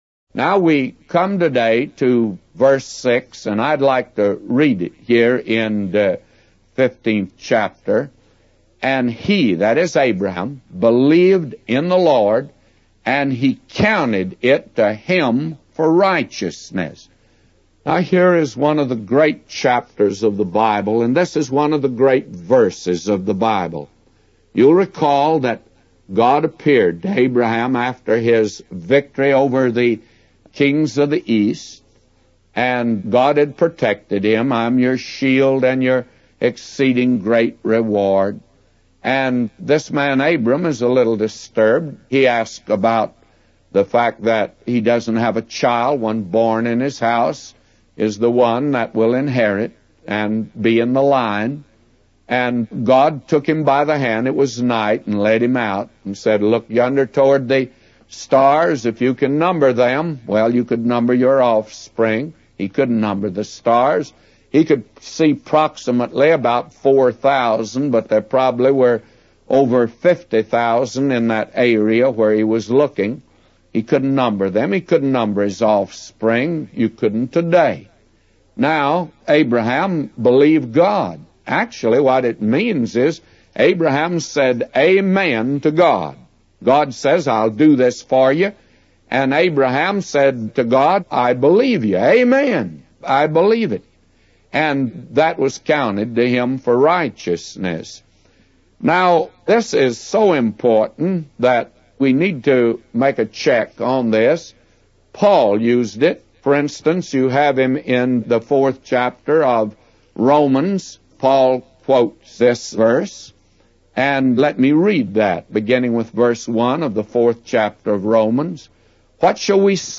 A Commentary By J Vernon MCgee For Genesis 15:6-999